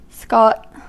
Ääntäminen
US
IPA : /skɒt/